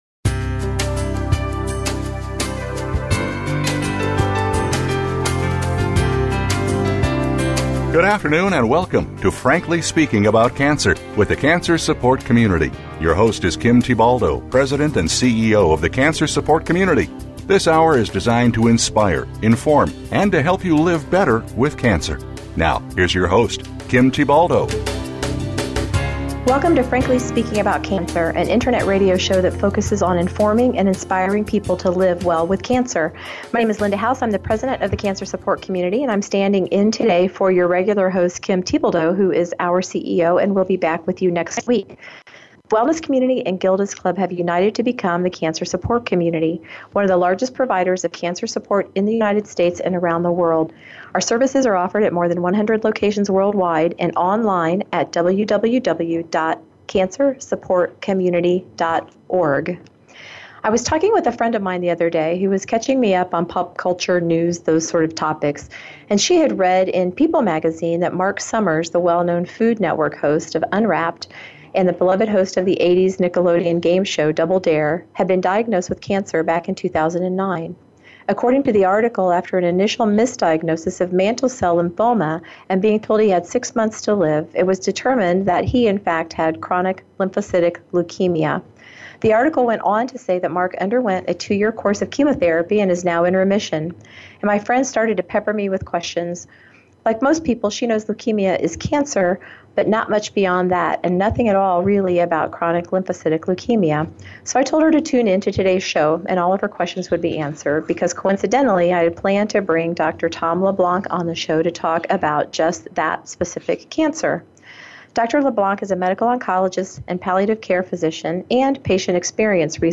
a comprehensive conversation about Chronic Lymphocytic Leukemia- a cancer that poses unique challenges.